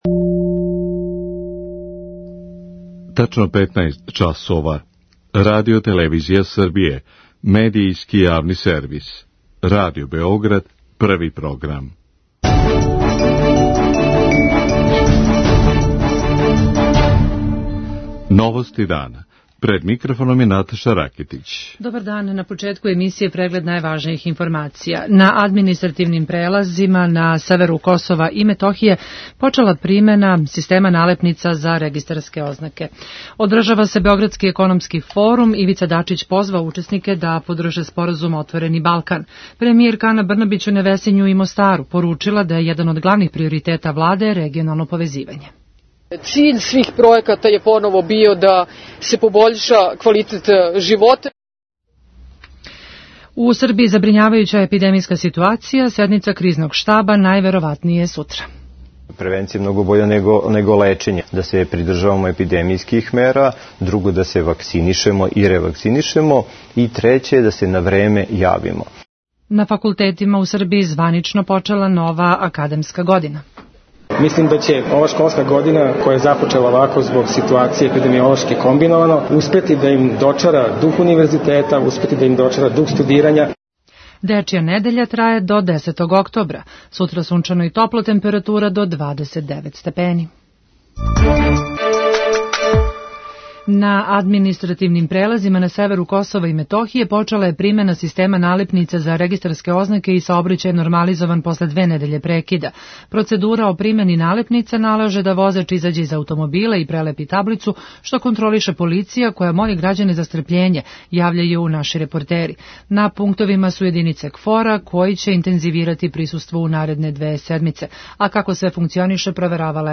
На административним прелазима на северу Косова и Метохије почела је примена система налепница за регистарске ознаке и саобраћај је нормализован после две недеље прекида. Процедура о примени налепница налаже да возач изађе из аутомобила и прелепи таблицу, што контролише полиција која моли грађане за стрпљење, јављају наши репортери са лица места.